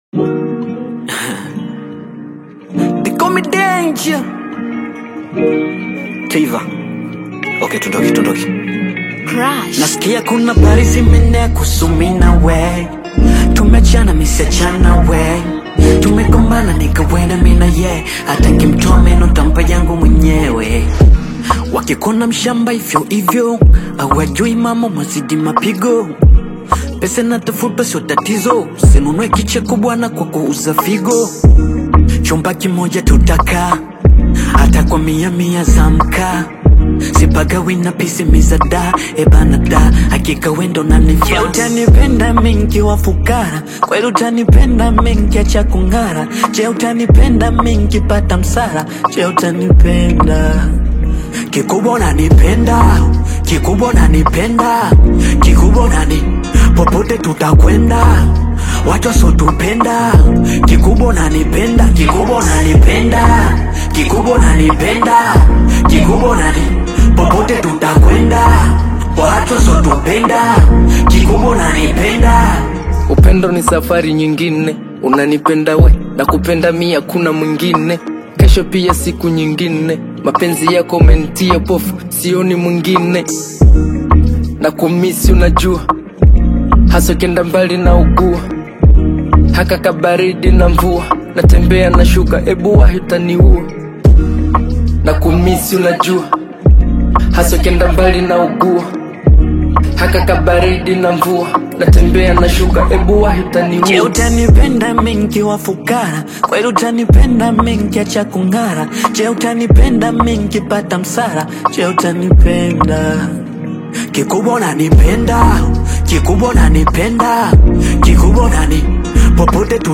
love song
smooth melody
vulnerable and expressive delivery